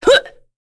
Morrah-Vox_Attack1.wav